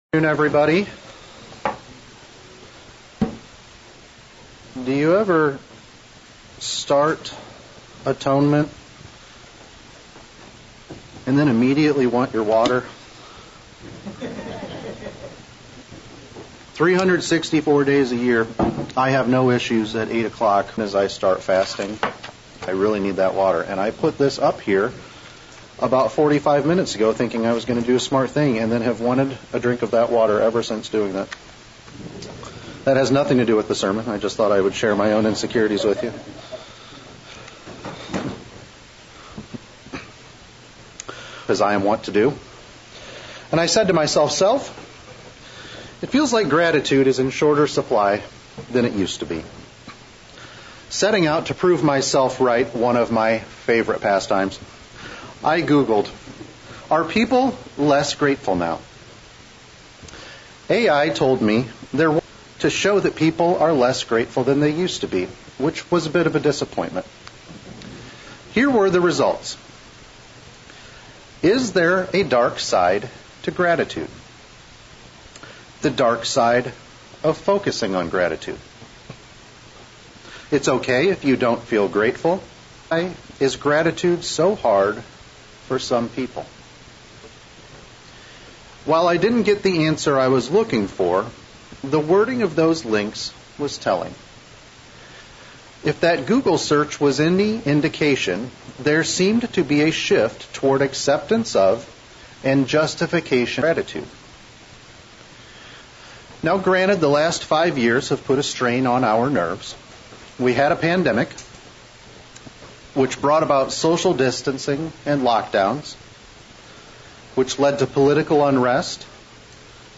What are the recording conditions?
Given in Central Illinois